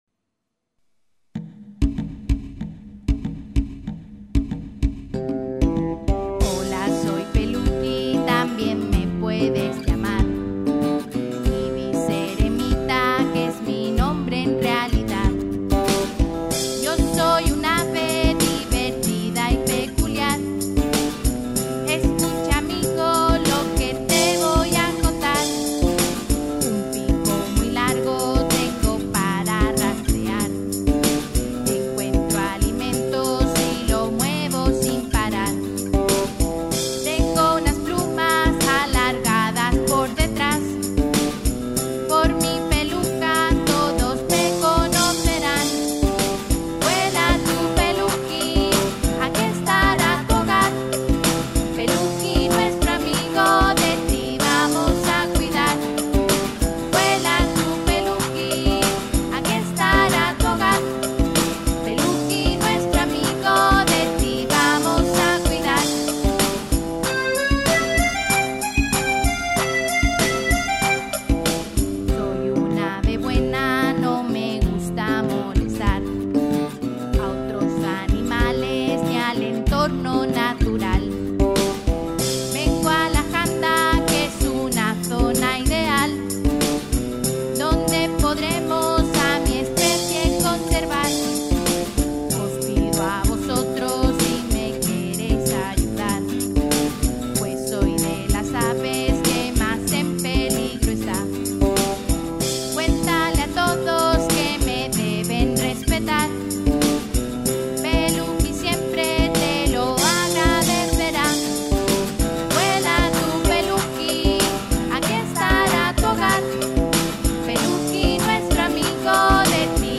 Peluki es la mascota del Proyecto Eremita y tiene una canción educastiva para concienciar a los más pequeños a través de la música.